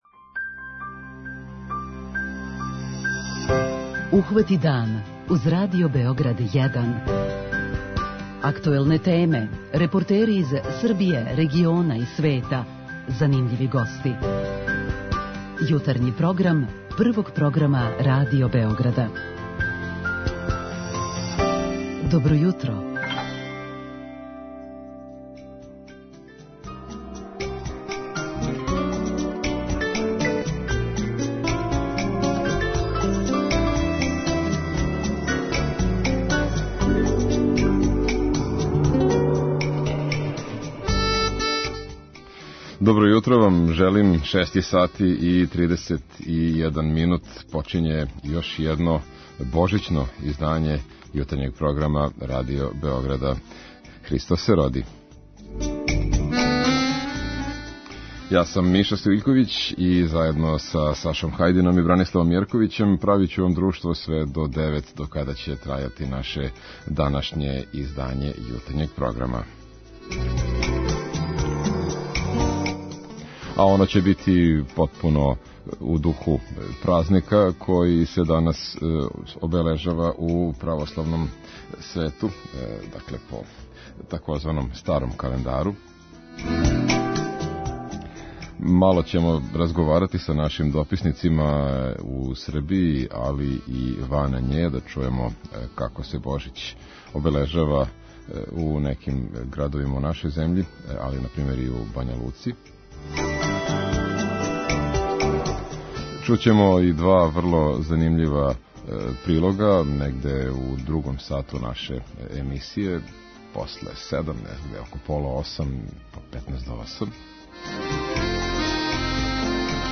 На Божићно јутро емитујемо Посланицу патријарха Иринеја и укључуемо дописнике Крушевца, Косовске Митровице и Бањалуке који нам преносе нам како протиче божићно јутро у тим градовима